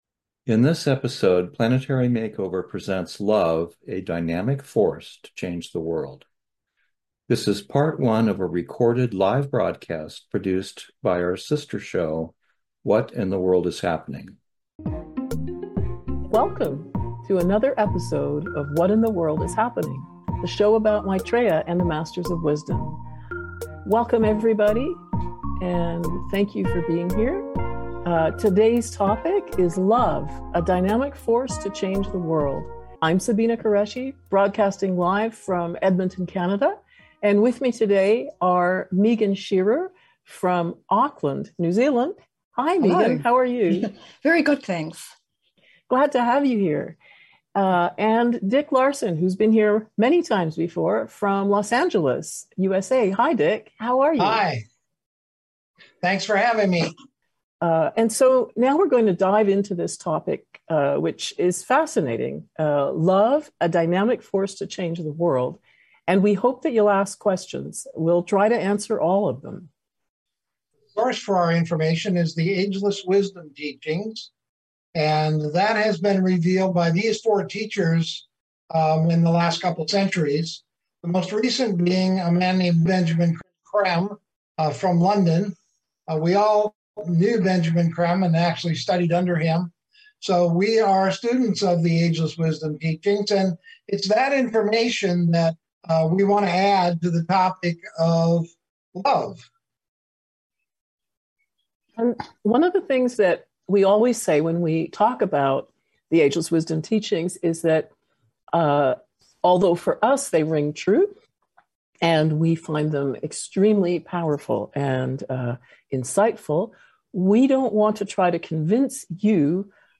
Talk Show Episode, Audio Podcast, Planetary MakeOver Show and Love, A Dynamic Force to Change the World, Part 1 on , show guests , about Love,Changing the World,Maitreya,Ageless Wisdom Teachings,Great Change on our Planet Earth,Role of Love, categorized as Earth & Space,Education,History,Paranormal,Philosophy,Physics & Metaphysics,Religion,Society and Culture,Theory & Conspiracy